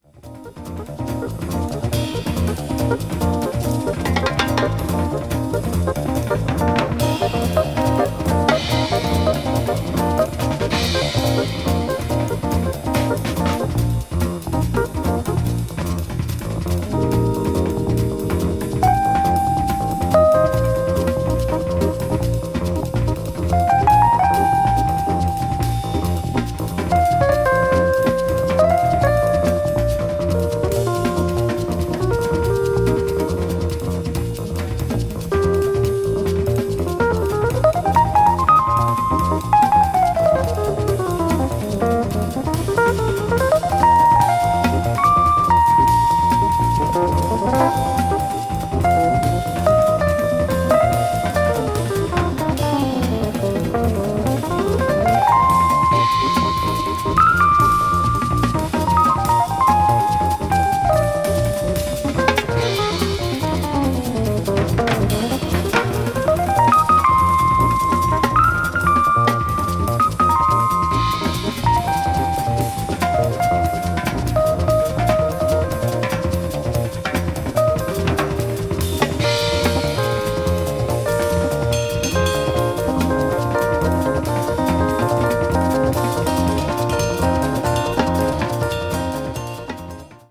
Recorded in a New York studio in 1974
electric bass
drums
percussion